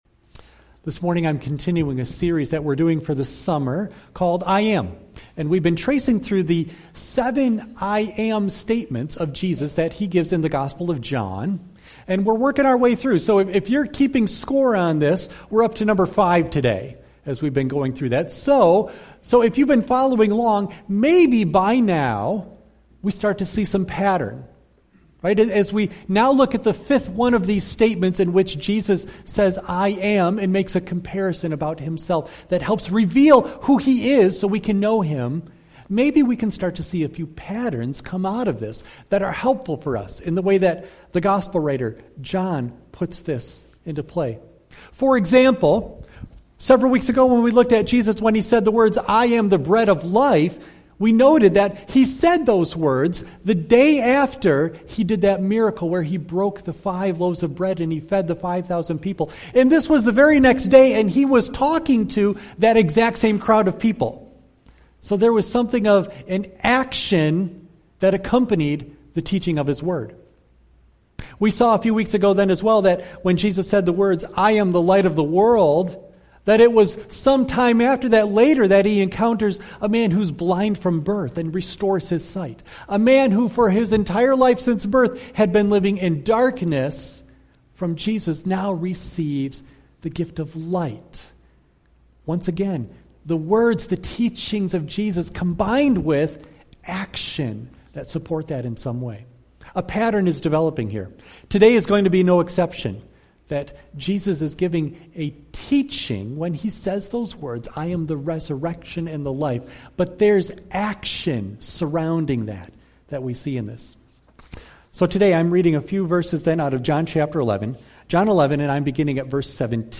John 11:17-27 Service Type: Sunday AM Bible Text